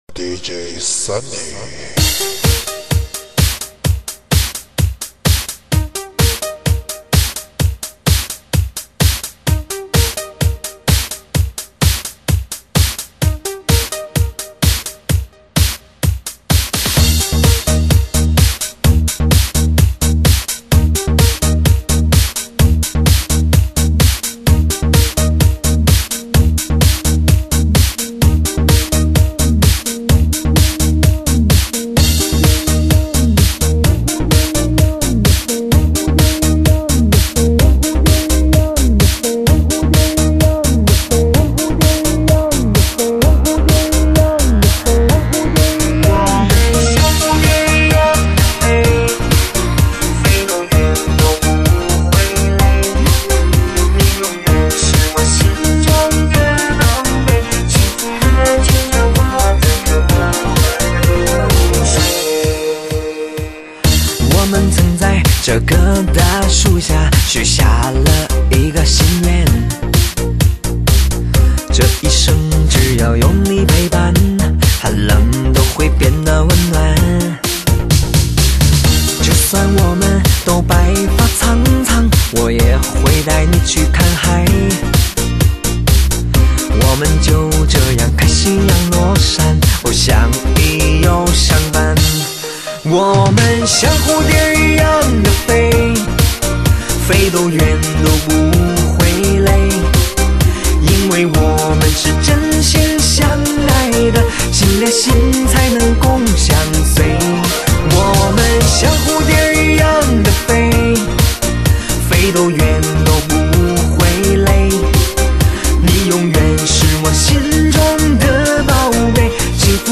慢嗨-最煽情的呻吟